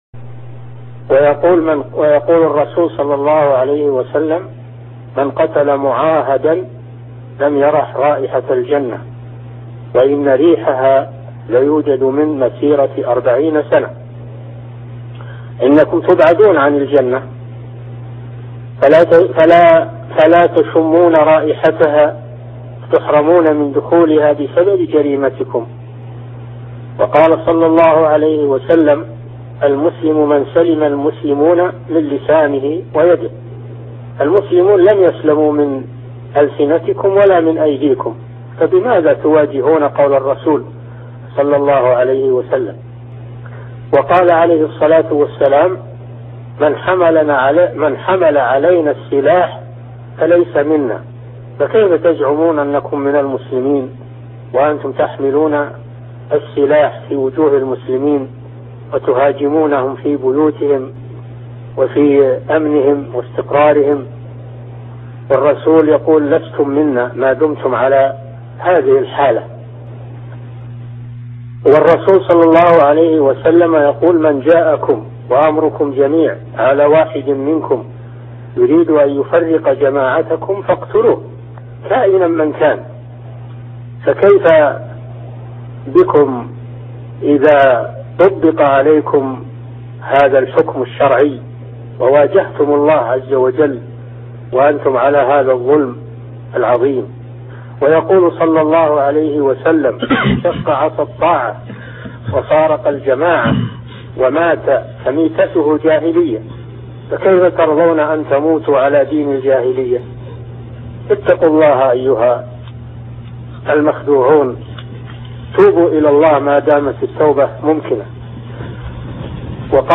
كلمة موجههة للمطلوبين أمنياً - الشيخ صالح الفوزان